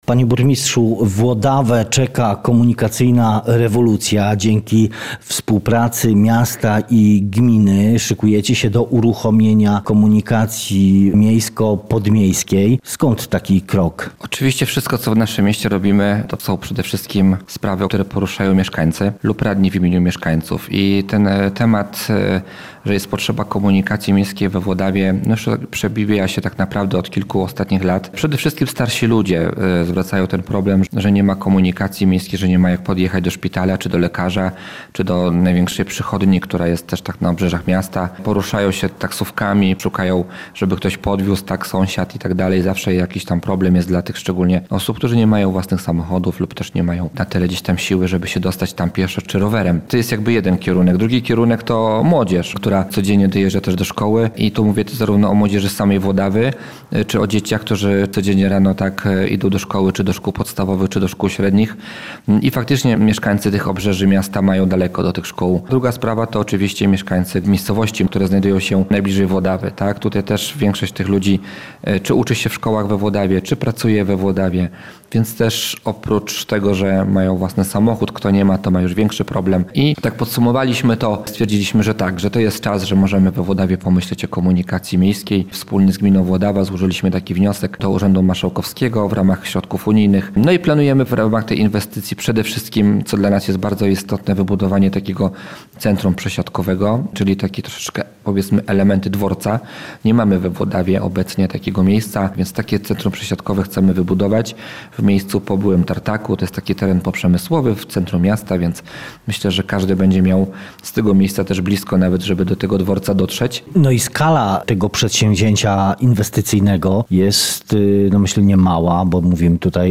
Rozmowa z burmistrzem Włodawy Wiesławem Muszyńskim